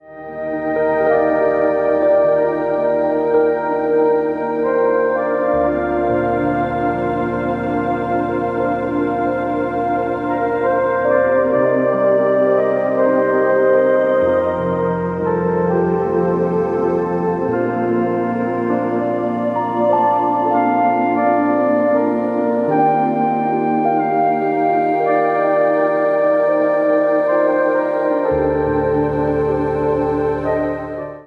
instrumental CD
birthed spontaneously during times of intimate worship